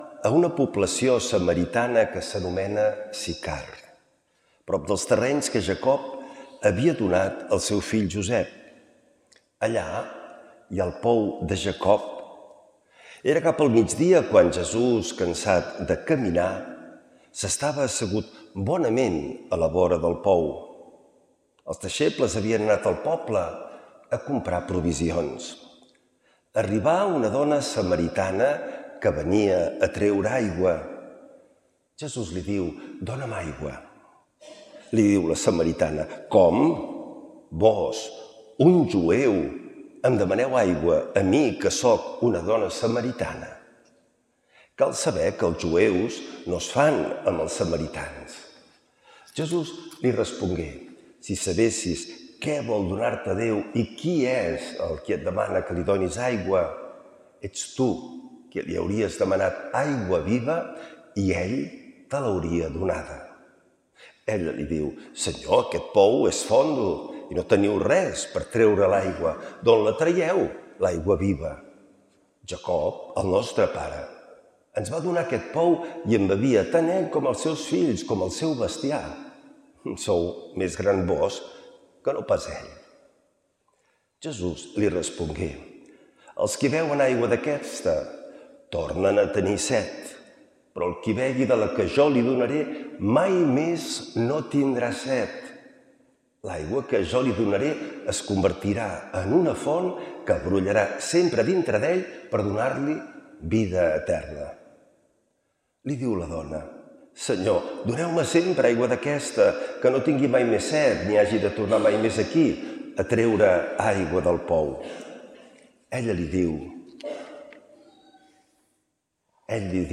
L’Evangeli i el comentari de diumenge 08 de març del 2026.
Lectura de l’Evangeli segons Sant Joan